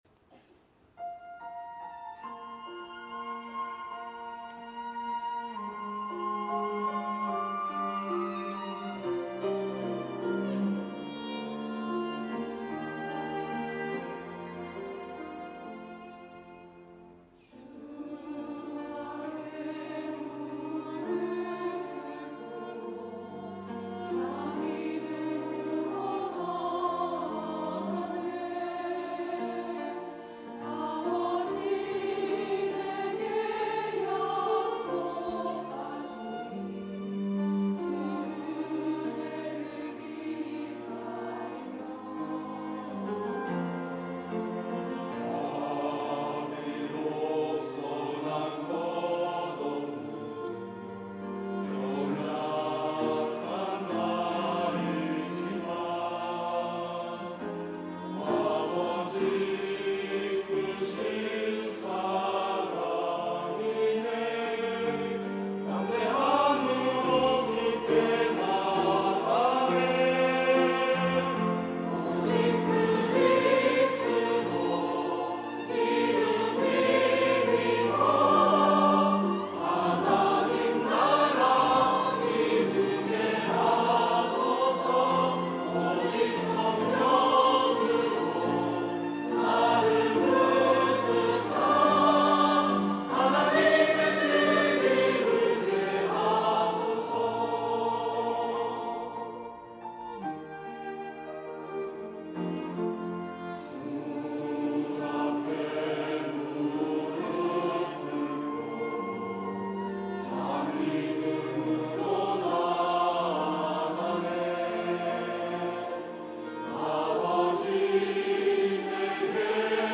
1부 찬양대